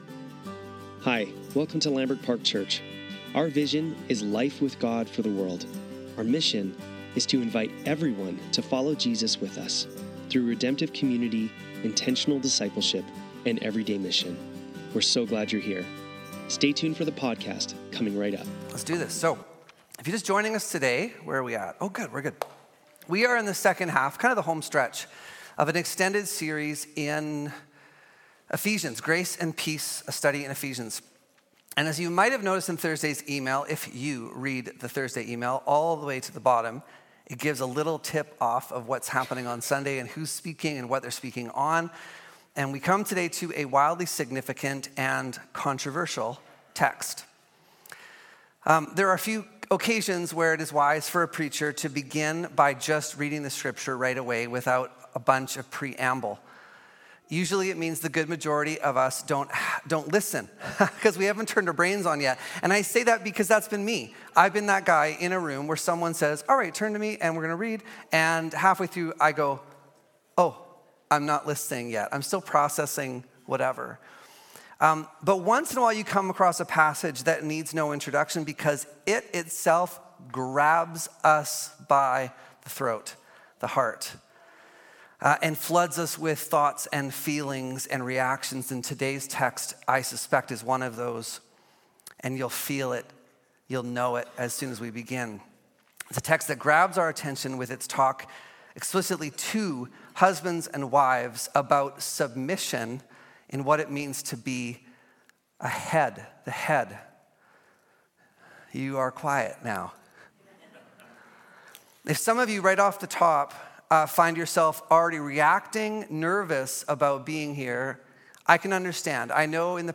Lambrick Sermons | Lambrick Park Church
Sunday Service - March 9, 2025